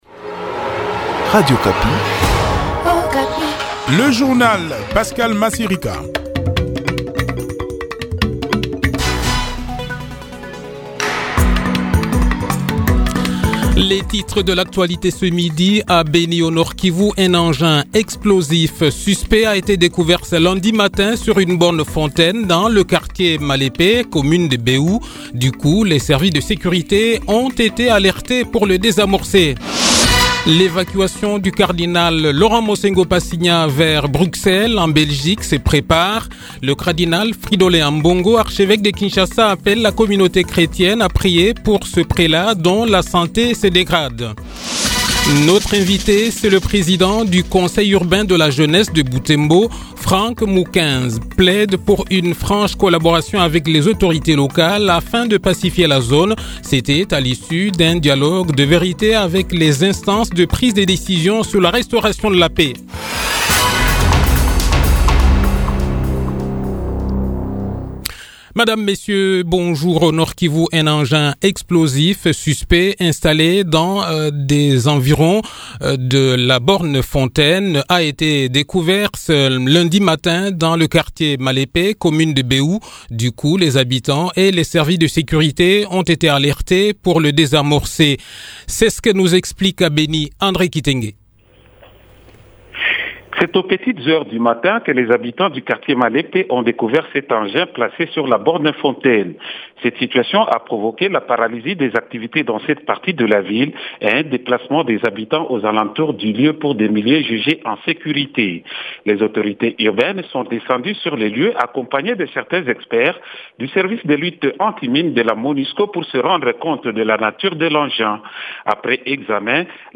Le journal de 12 h, 5 Juillet 2021